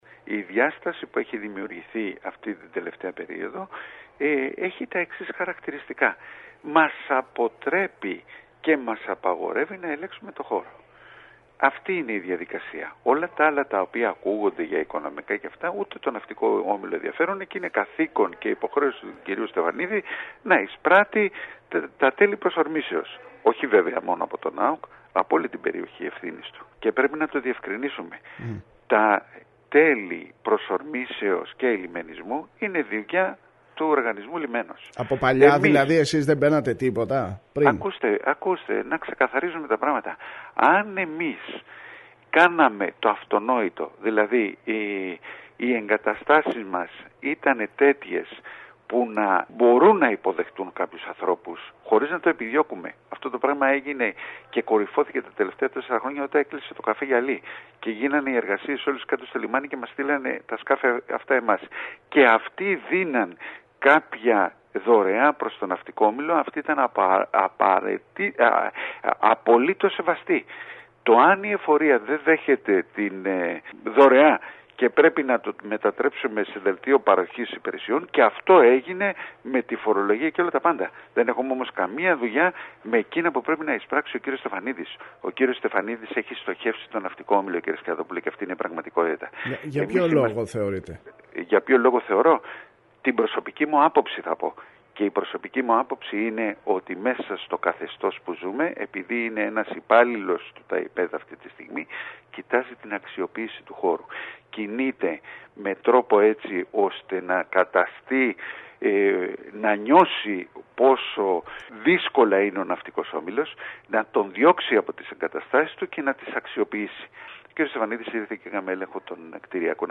ΡΕΠΟΡΤΑΖ